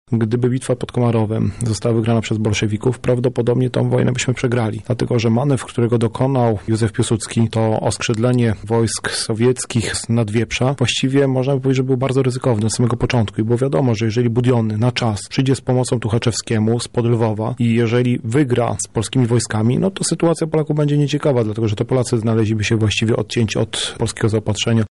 historyk.